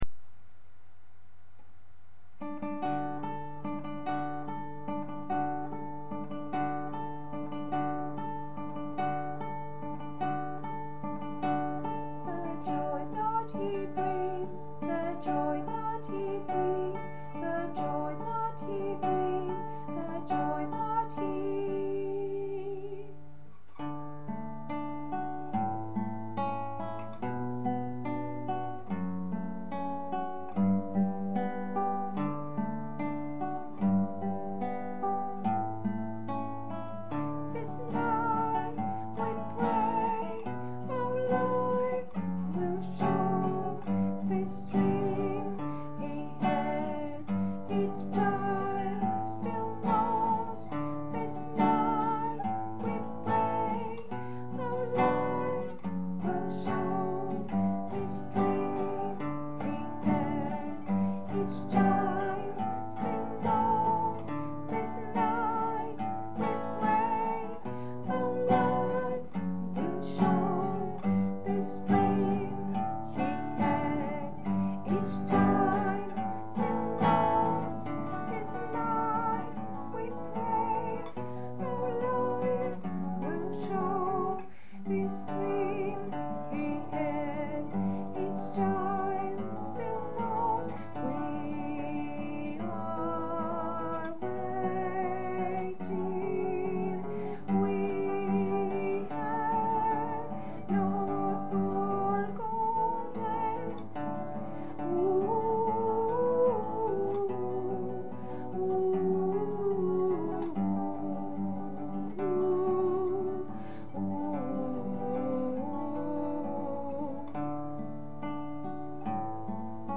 MP3 ΓΙΑ ΕΞΑΣΚΗΣΗ ΤΩΝ ΧΟΡΩΔΩΝ